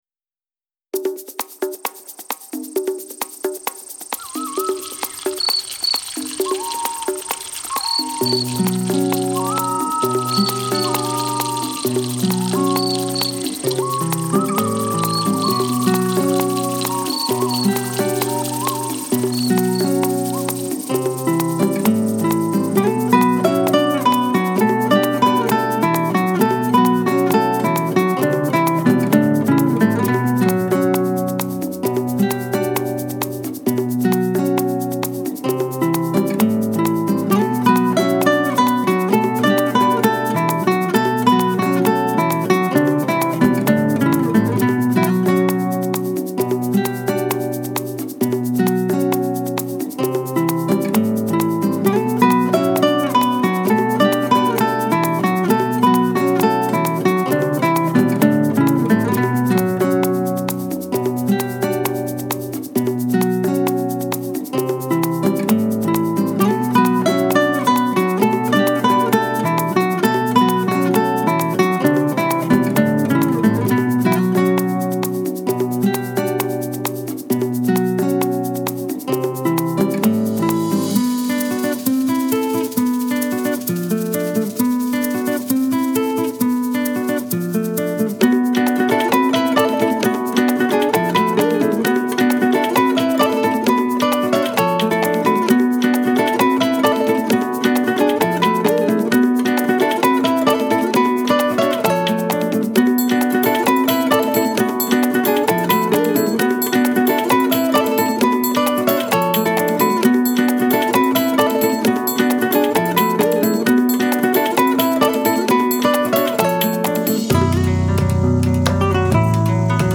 موسیقی بی کلام گیتار
موسیقی بی کلام شاد موسیقی بی کلام فلامنکو